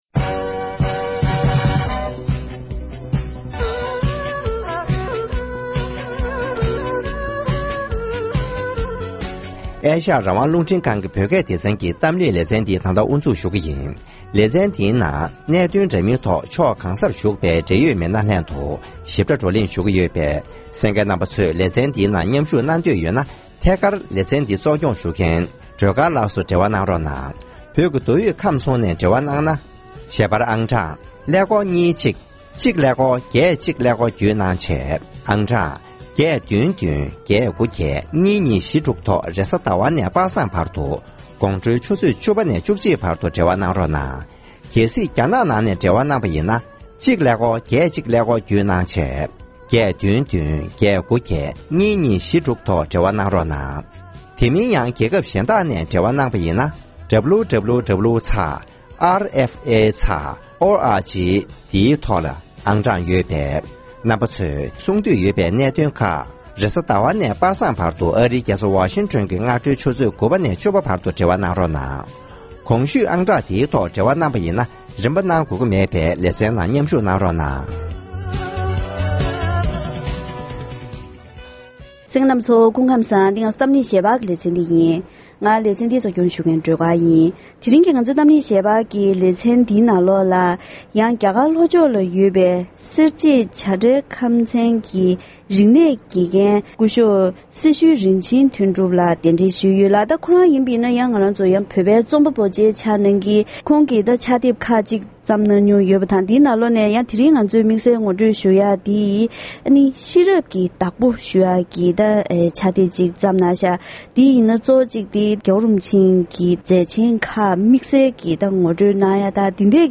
ནང་ཆོས་དང་ཚན་རིག ཡ་རབས་སྤྱོད་བཟང་སོགས་ཀྱི་སྐོར་རྩོམ་པ་པོ་དང་ལྷན་བཀའ་མོལ་ཞུས་པ་ཞིག་གསན་རོགས་གནང་།